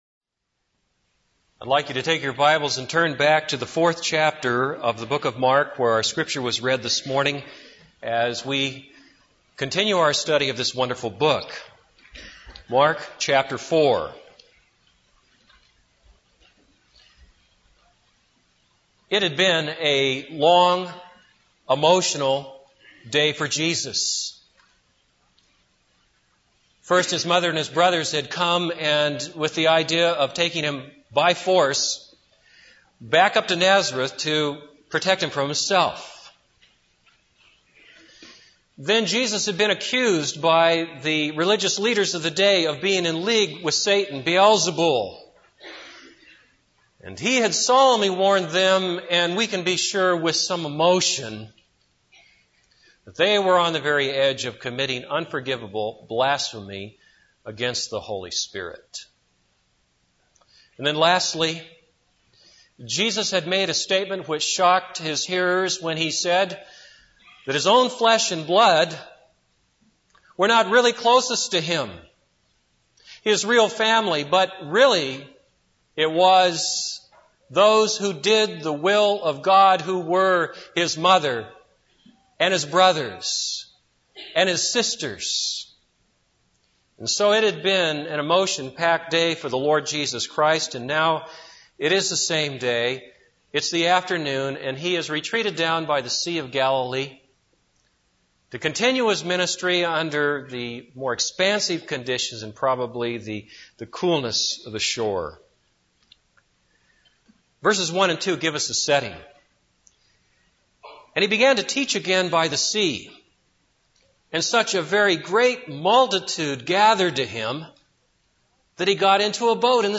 This is a sermon on Mark 4:1-20.